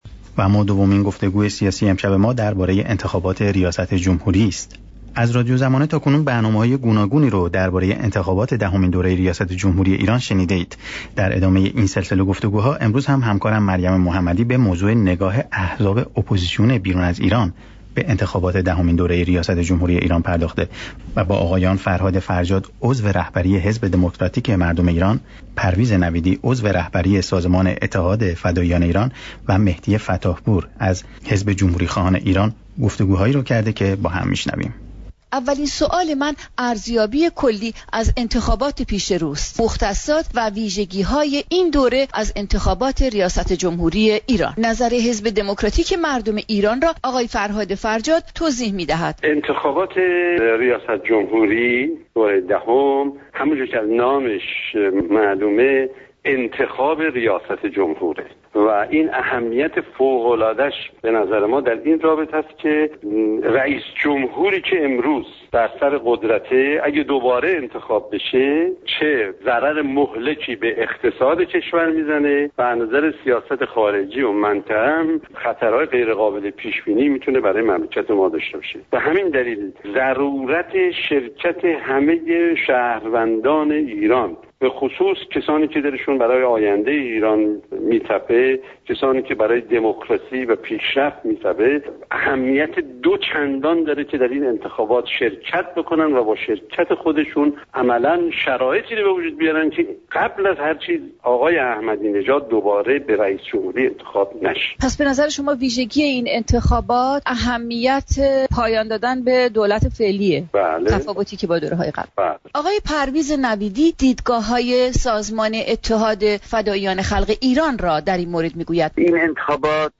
فایل صوتی را از همین جا بشنوید: اولین سوالم، ارزیابی کلی از انتخابات پیش رو است؛ این دوره از انتخابات ریاست جمهوری ایران چه مختصات و ویژگیهایی دارد؟